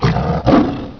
Demon
Sight